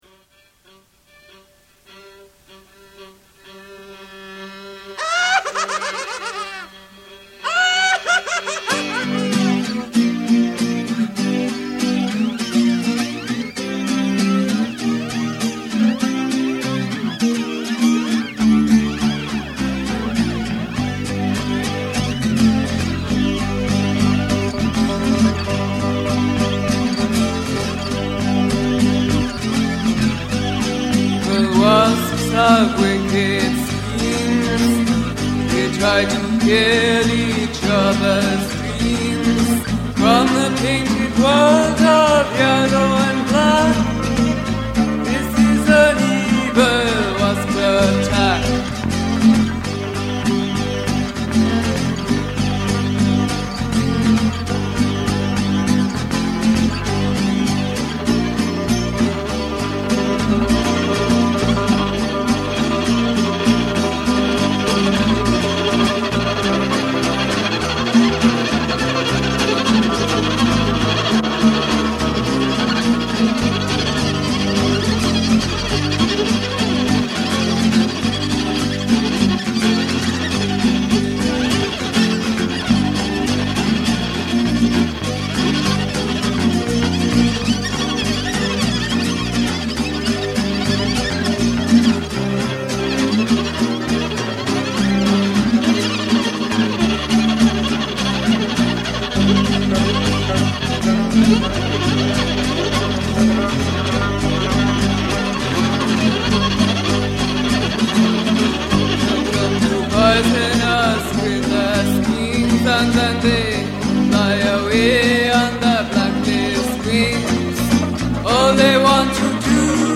Viola
Guitar + Bass Guitar